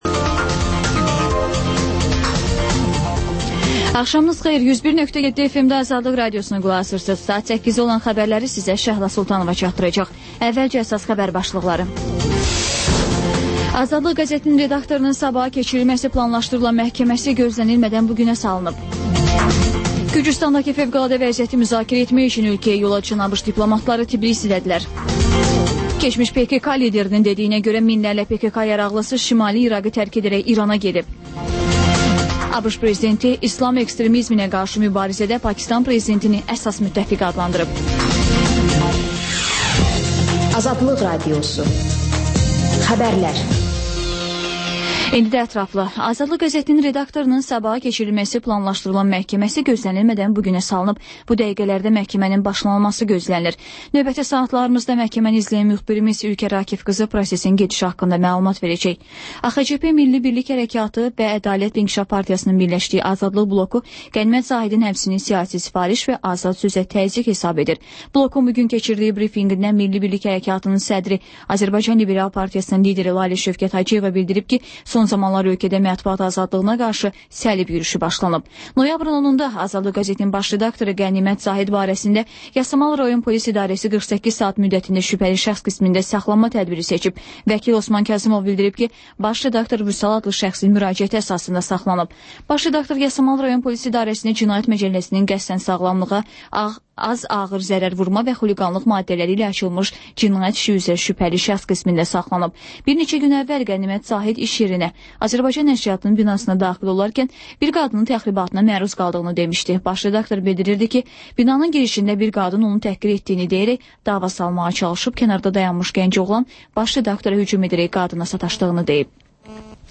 Xəbərlər, İZ: Mədəniyyət proqramı və TANINMIŞLAR rubrikası: Ölkənin tanınmış simalarıyla söhbət